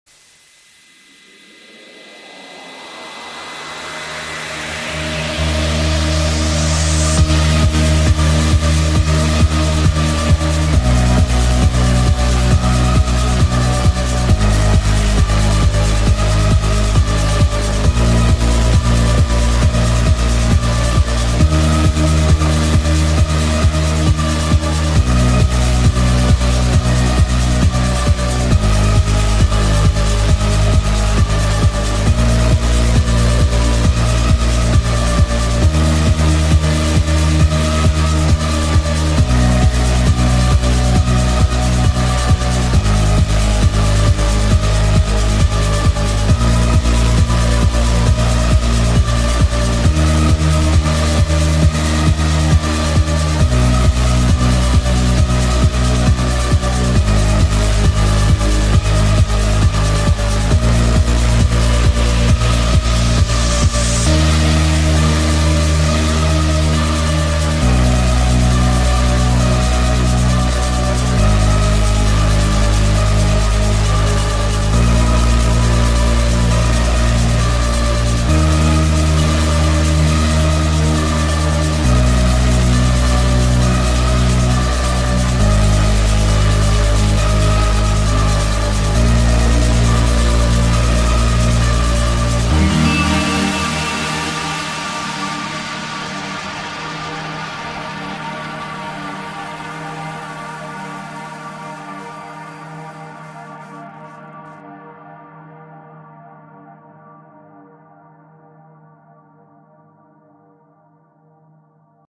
динамичная мелодия